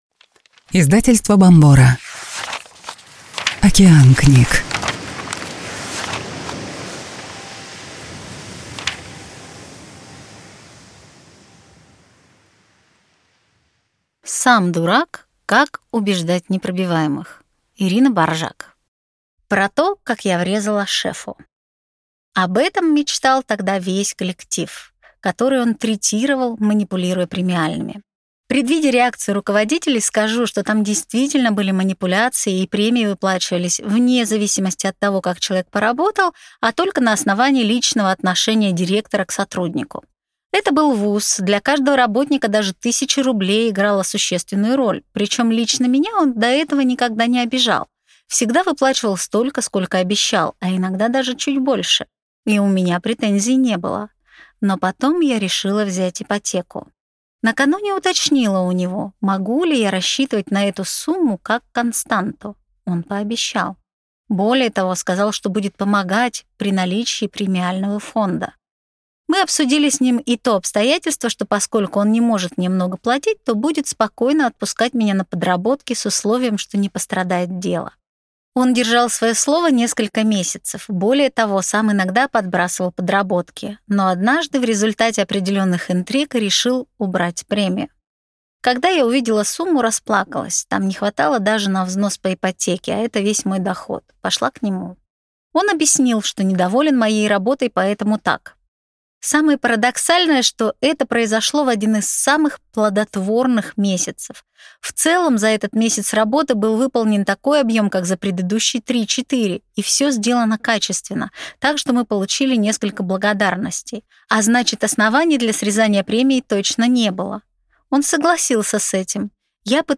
Аудиокнига Сам дурак. Как убеждать непробиваемых | Библиотека аудиокниг